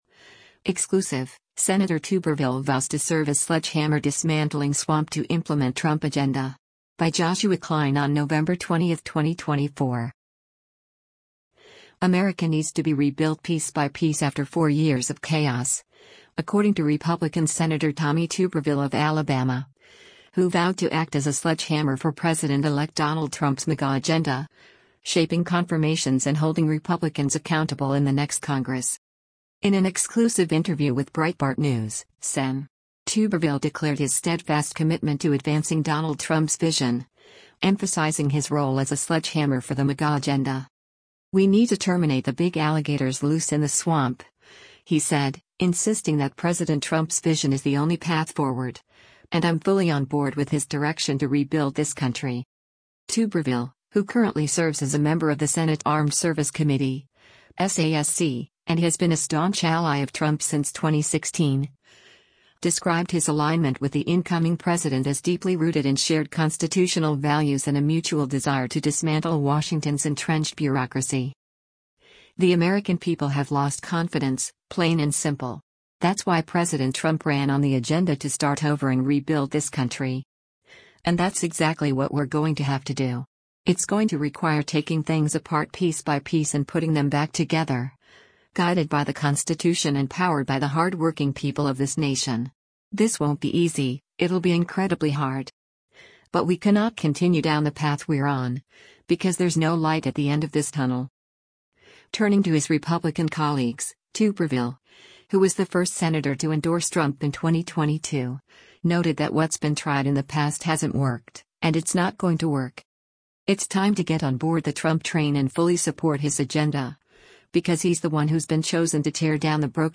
In an exclusive interview with Breitbart News, Sen. Tuberville declared his steadfast commitment to advancing Donald Trump’s vision, emphasizing his role as a “sledgehammer” for the MAGA agenda.